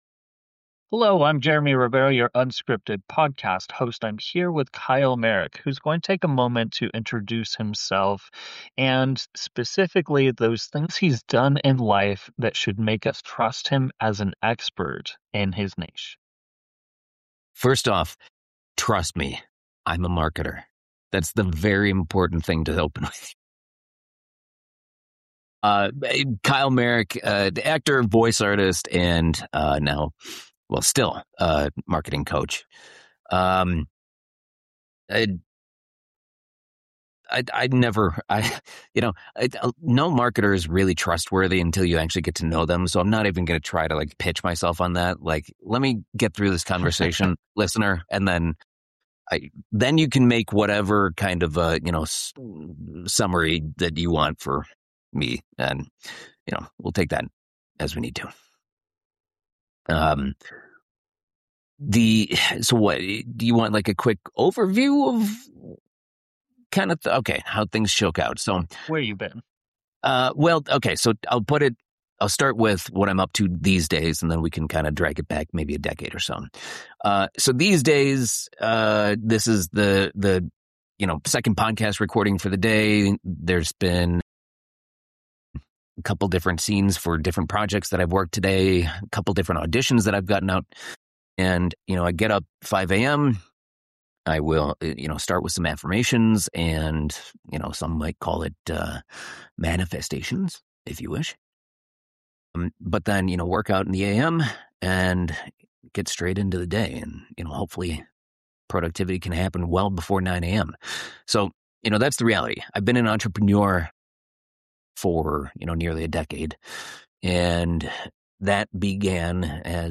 What happens when a marketing coach who's also an actor sits down to talk about authenticity in business? You get one of the most unfiltered conversations about trust, AI, and human connection in marketing that we've had on this show.